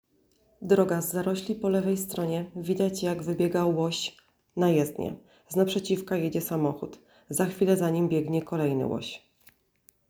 Nagranie audio audiodeskrypcja_filmu